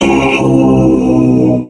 robo_bo_die_05.ogg